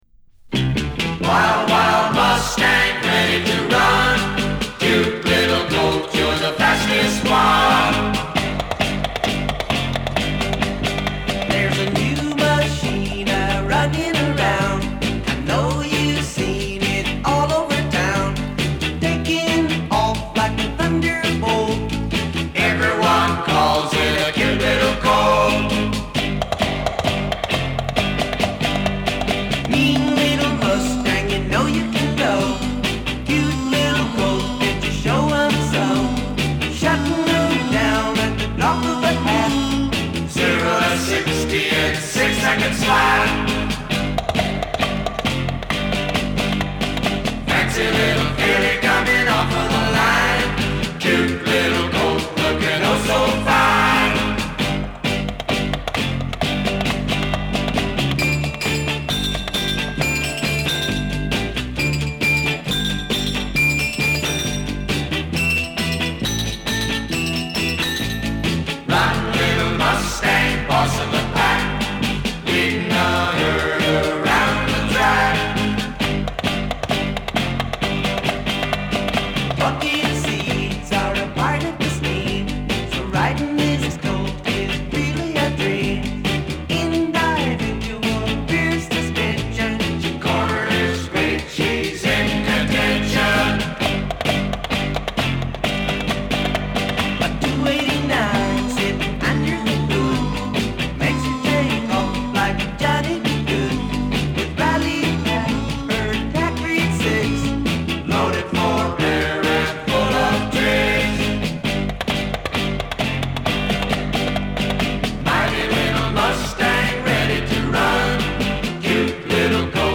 サーフ・コーラス全盛の最中、セッション・プレイヤーの力が最大限に発揮された質の高いナンバー。
ピュアでストレート、余計な装飾のないサウンド・プロダクションが魅力的だ。